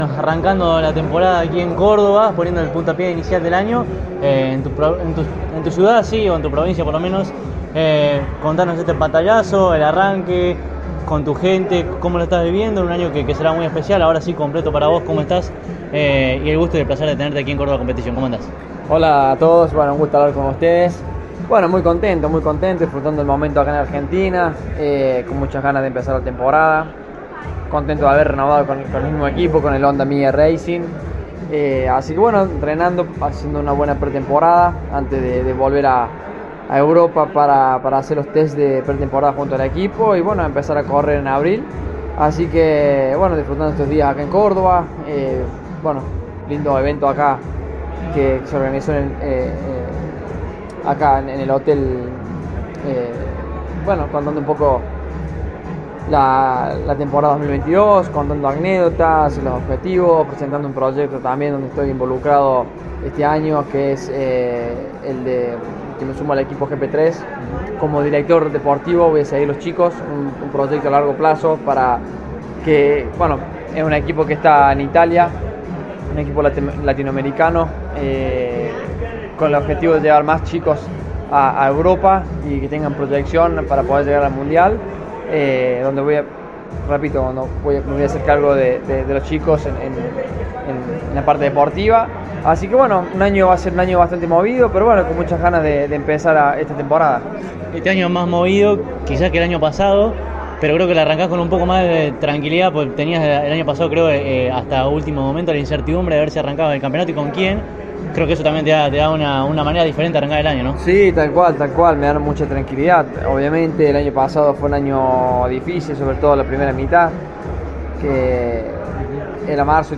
En la presentación de su temporada 2022, Leandro “Tati” Mercado dialogó unos minutos con CÓRDOBA COMPETICIÓN.